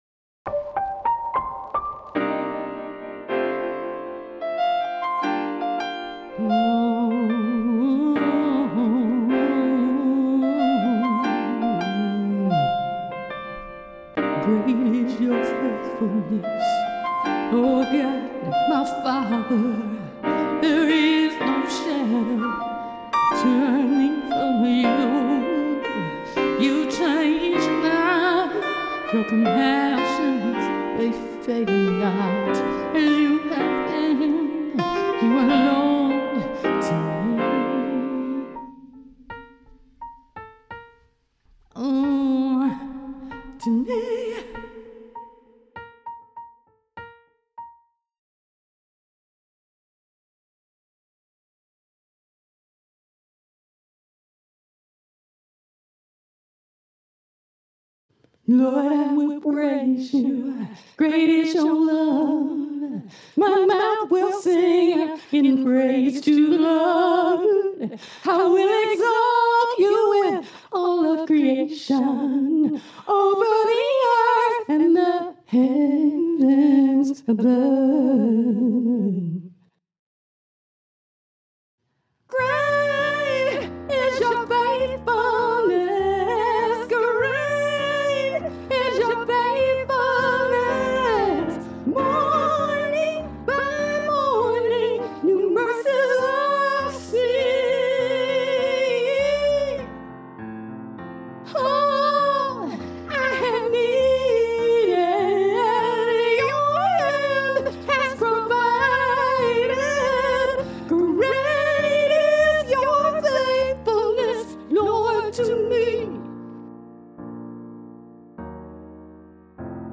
is an urban, black gospel adaptation of the hymn